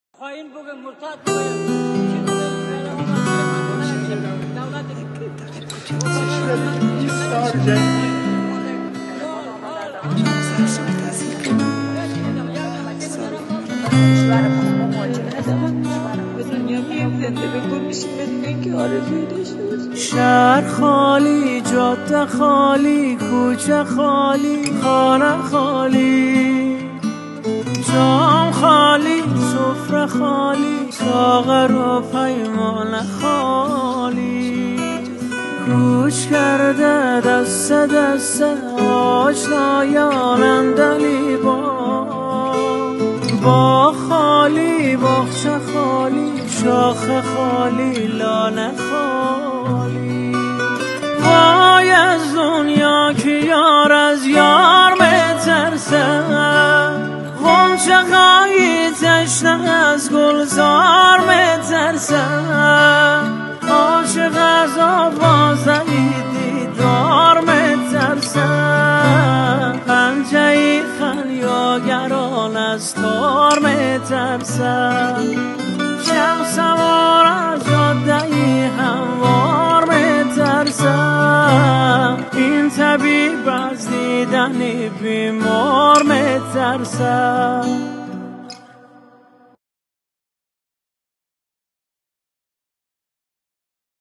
کامل با گیتار